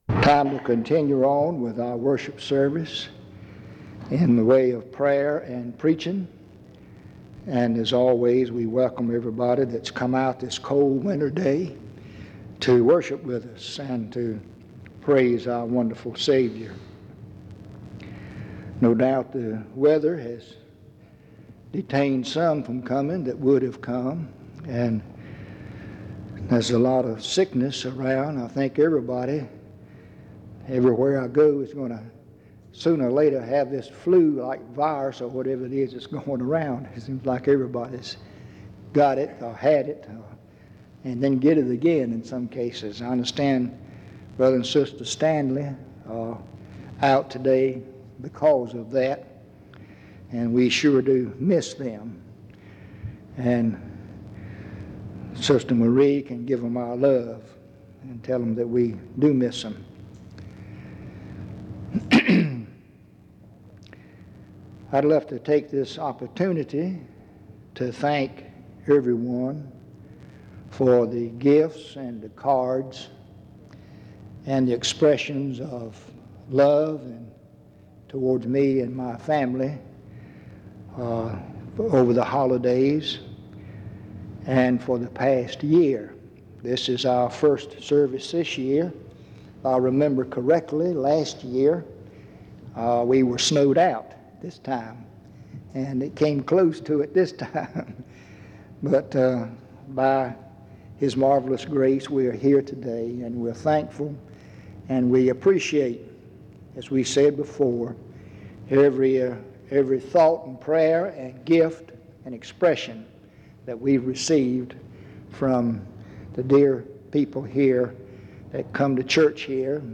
Em Collection: Reidsville/Lindsey Street Primitive Baptist Church audio recordings Miniatura Título Data de carga Acesso Ações PBHLA-ACC.001_014-B-01.wav 2026-02-12 Baixar PBHLA-ACC.001_014-A-01.wav 2026-02-12 Baixar